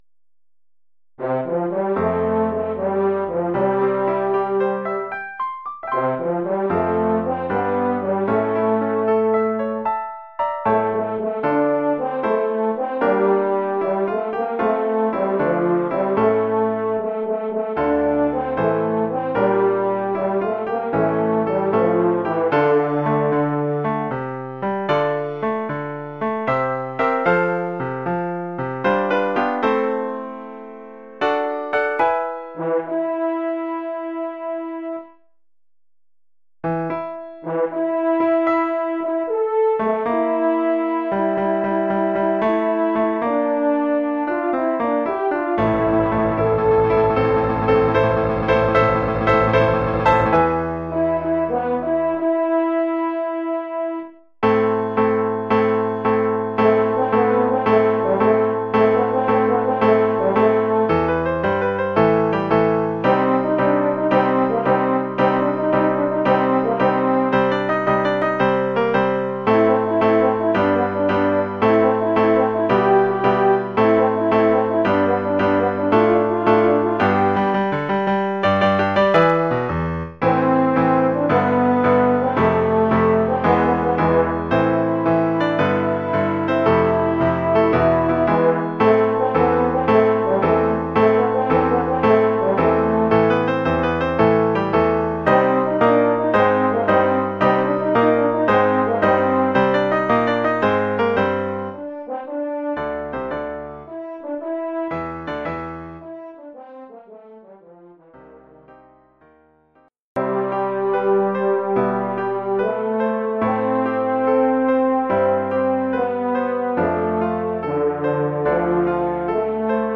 Oeuvre pour cor d’harmonie et piano.